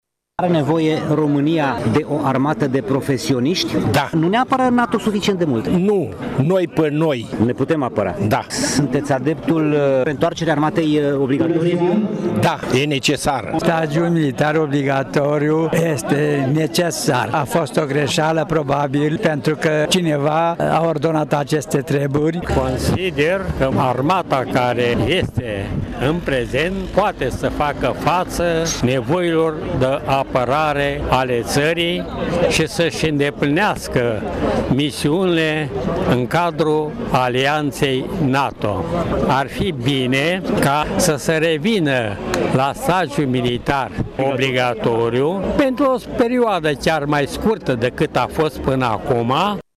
Și militarii de carieră, veterani și în rezervă susțin revenirea la stagiul militar obligatoriu, chiar dacă unul de scurtă durată, pentru o minimă pregătire a societății civile: